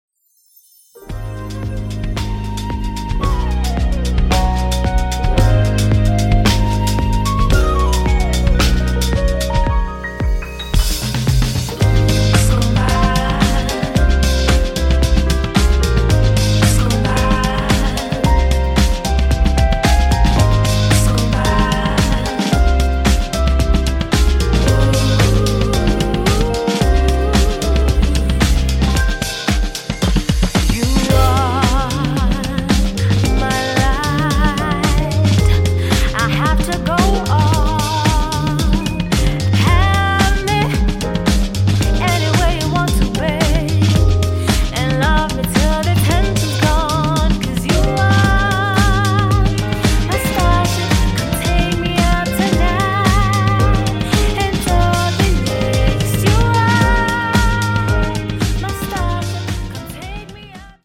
Latin flavoured longplayer